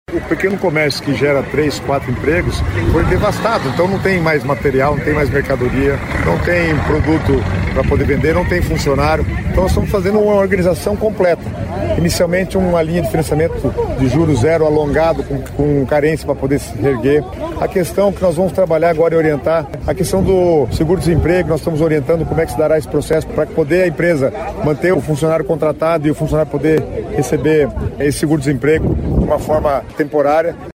O secretário das Cidades, Guto Silva, falou sobre uma série de iniciativas para ajudar trabalhadores e pequenas empresas.